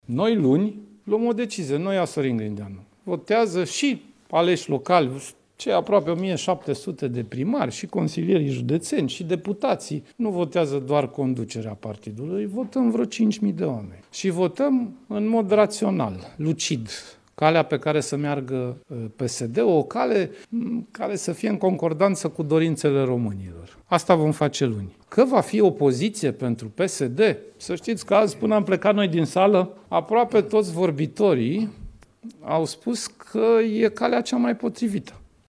Liderul PSD, Sorin Grindeanu, într-o conferință de presă la reuniunea partidului din Sighetu Marmației: „Noi, luni, luăm o decizie. N-o ia Sorin Grindeanu”